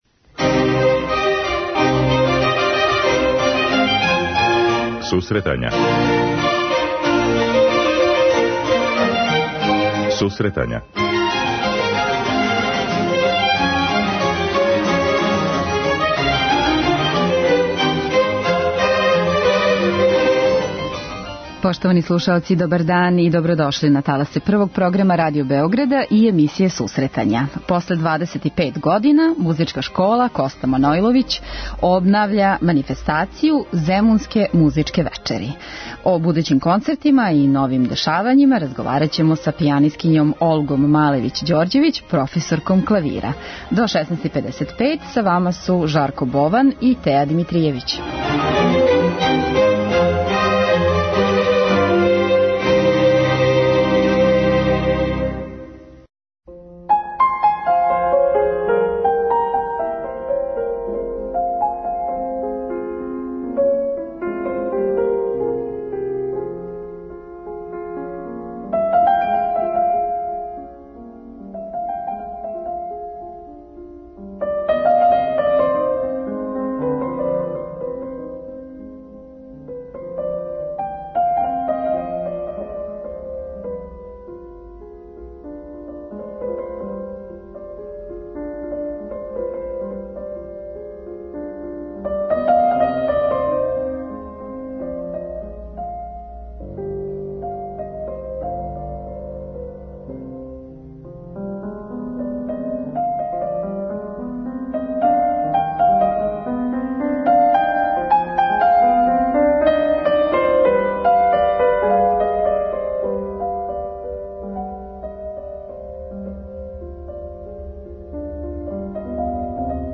преузми : 9.65 MB Сусретања Autor: Музичка редакција Емисија за оне који воле уметничку музику.